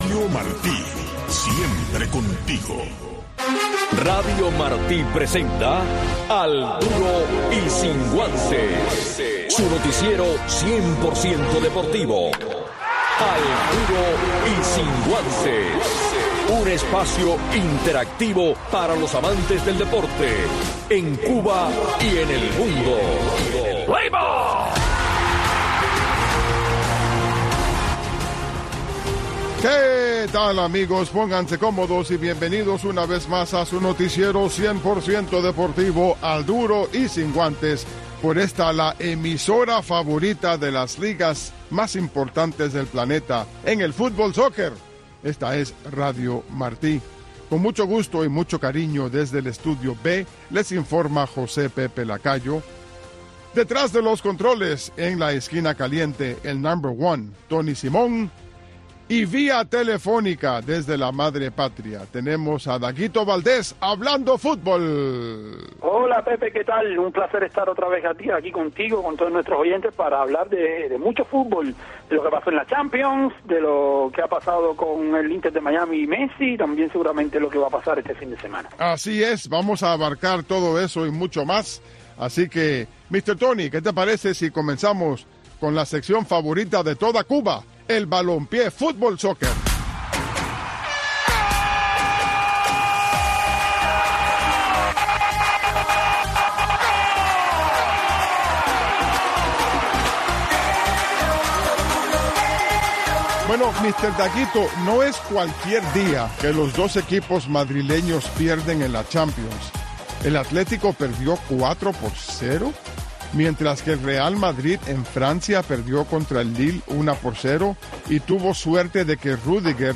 Un resumen deportivo en 60 minutos